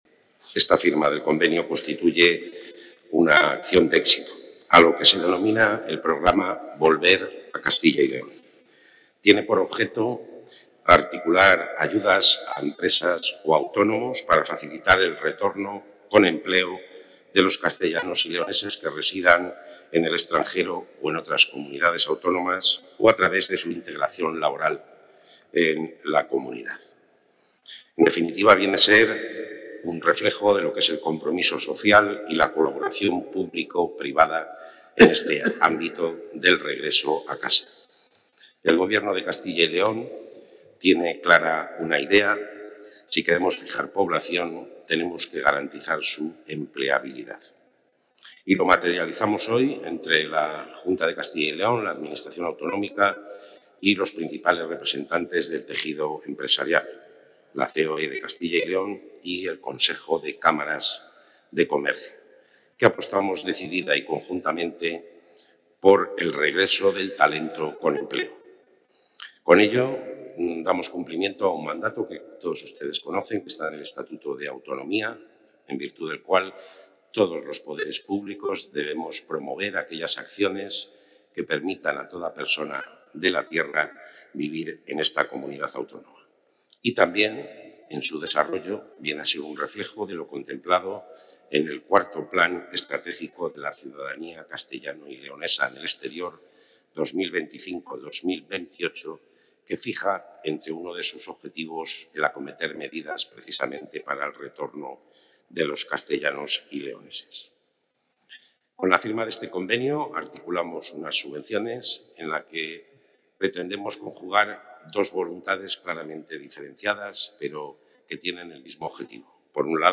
Intervención del consejero.